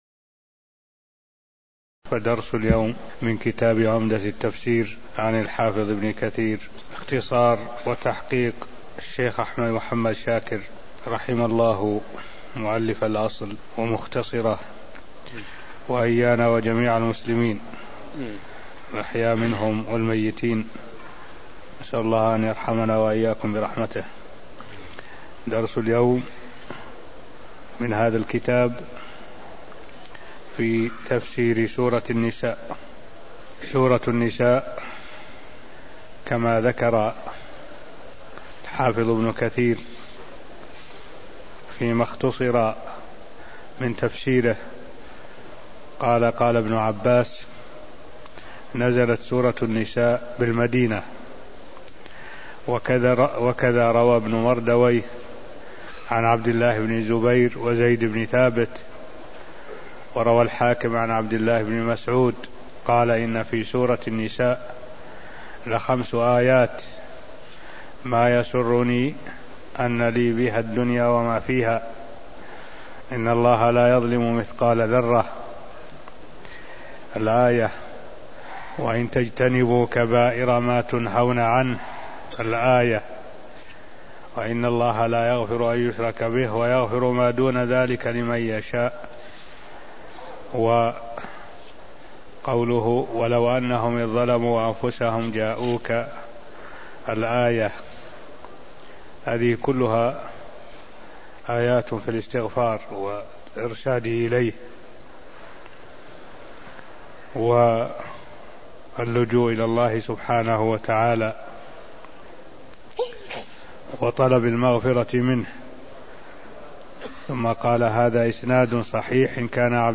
المكان: المسجد النبوي الشيخ: معالي الشيخ الدكتور صالح بن عبد الله العبود معالي الشيخ الدكتور صالح بن عبد الله العبود سورة النساء 1 (0203)